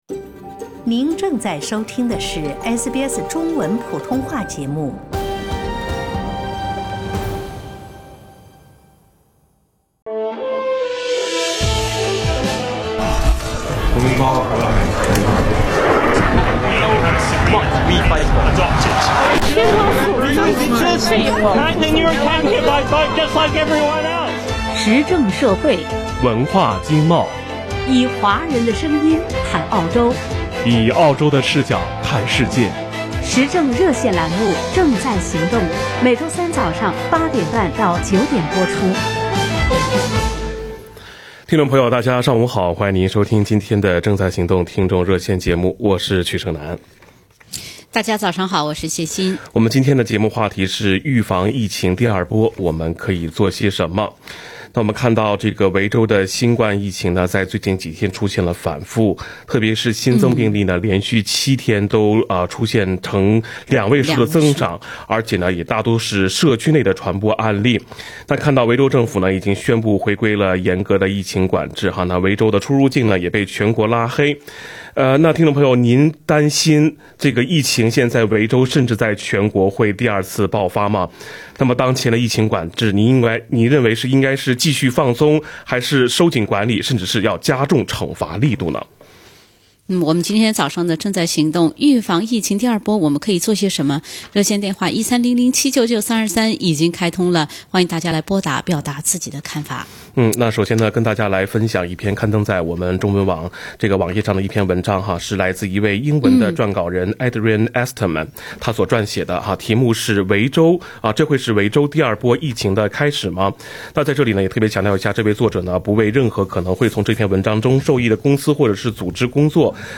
你担心疫情在维州甚至在全国二次爆发吗？州政府管理得好不好？澳洲华人有什么担心？（点击上方图片收听电台热线讨论）